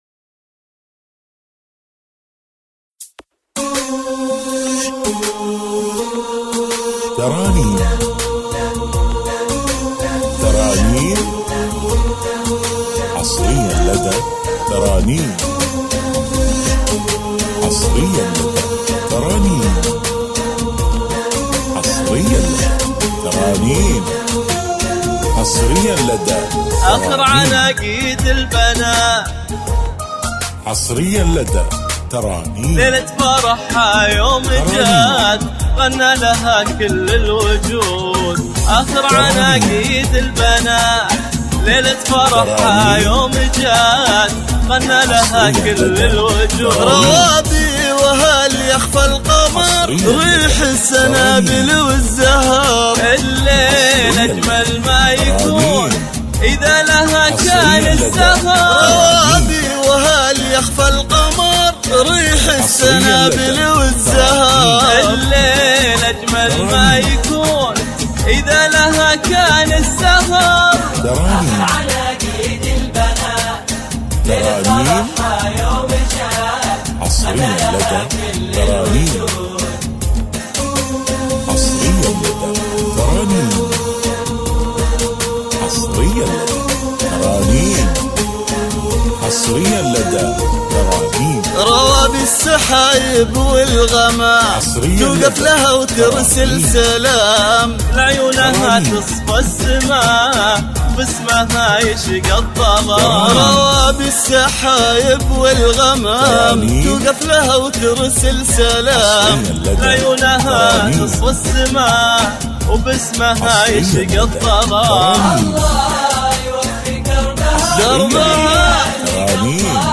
زفات